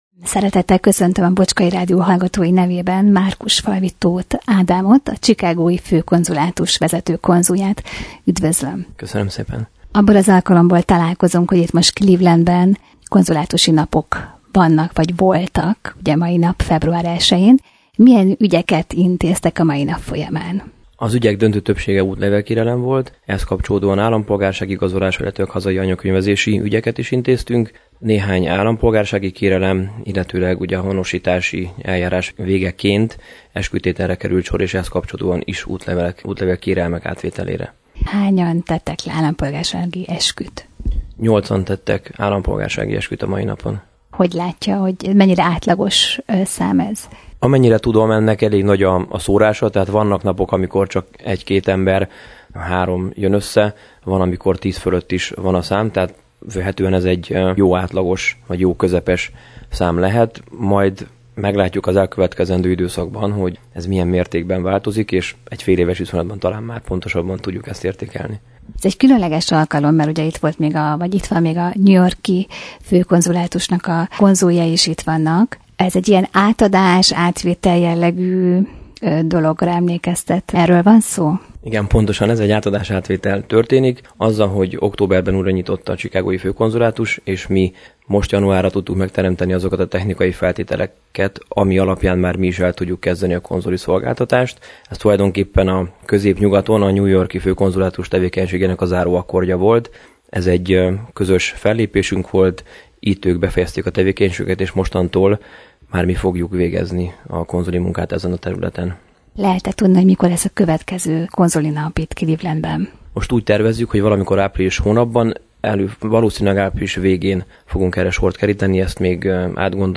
Interjú Márkusfalvi -Tóth Ádámmal, a Chicago-i Magyar Főkonzulátus vezető konzuljával – Bocskai Rádió